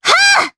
Cleo-Vox_Attack3_jp.wav